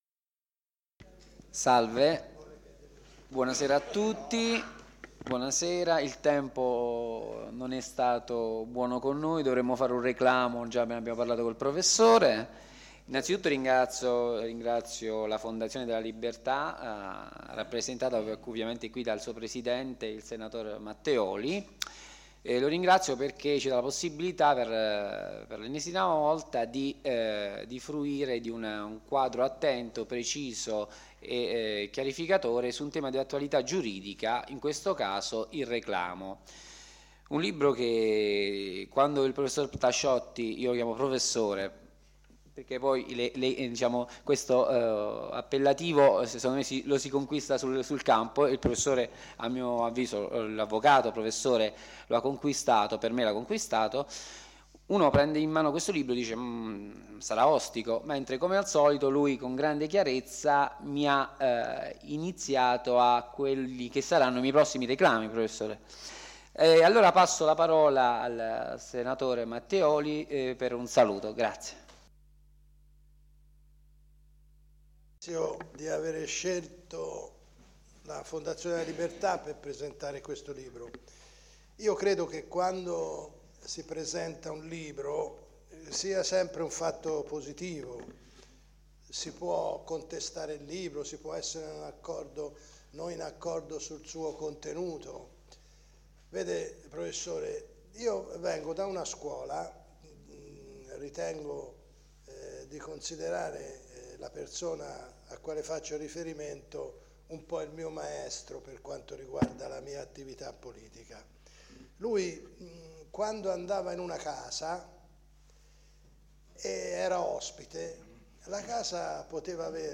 AUDIO: PRESENTAZIONE DEL LIBRO “IL RECLAMO DALL’ORIGINE”